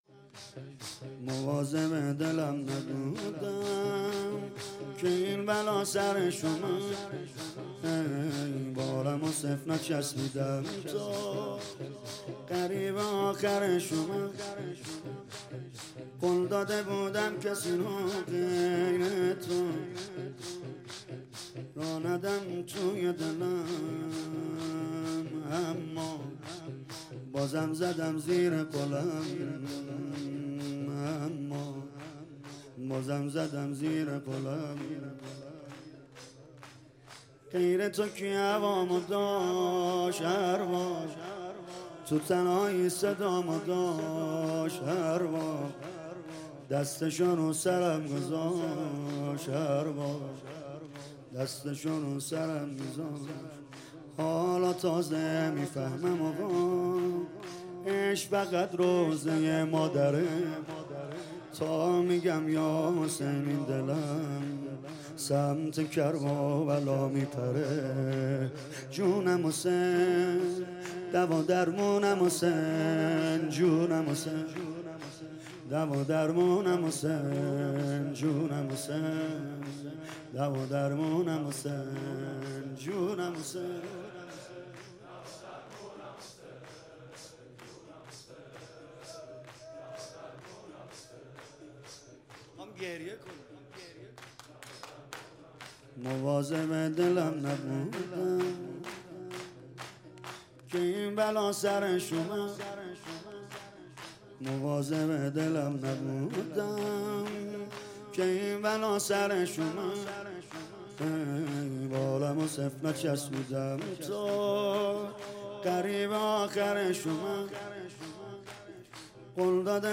مواظب دلم نبودم - شور